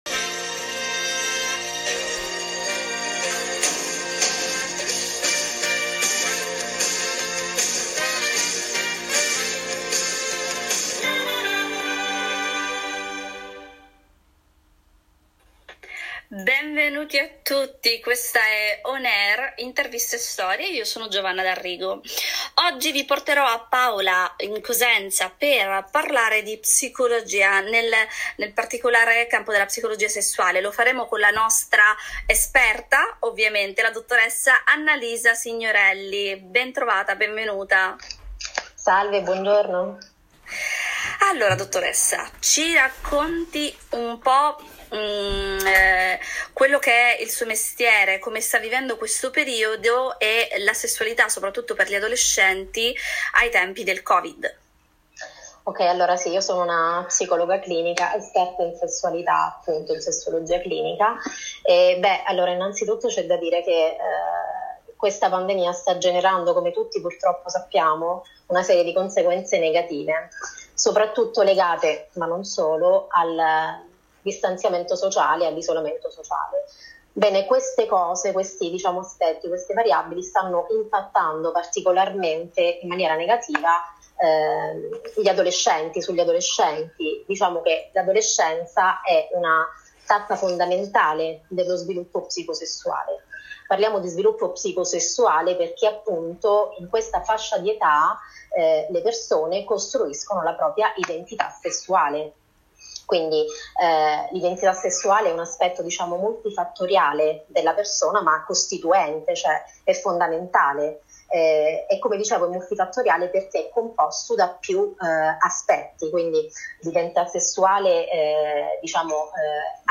Intervista radiofonica Rai RadioNews24 – Adolescenti e sessualità ai tempi del Covid. Implicazioni ed indicazioni pratiche.